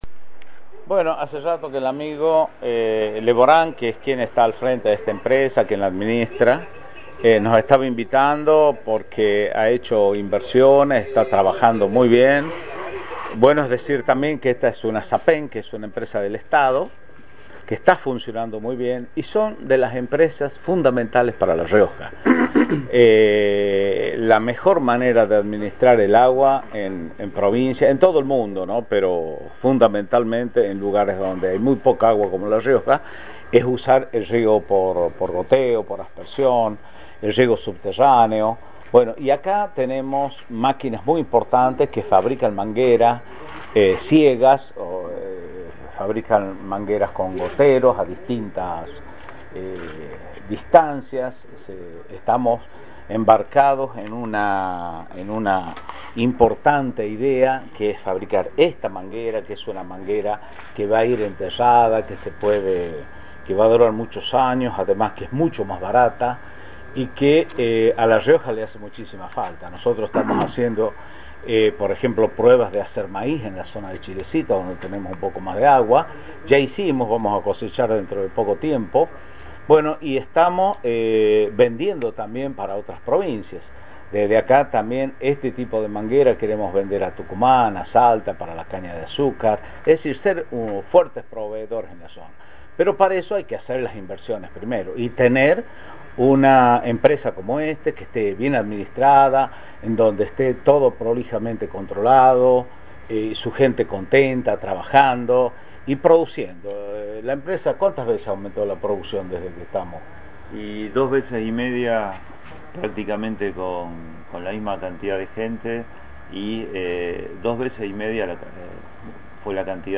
Luis Beder Herrera, gobernador de La Rioja
El gobernador visito en la mañana de este miércoles la firma DRIPSA SAPEM, dedicada a la producción de insumos para riego por goteo, en donde informó que cabe la posibilidad que el Estado provincial otorgue durante 2013 dos o tres aumentos de salarios para los agentes de la Administración Pública Provincial (APP).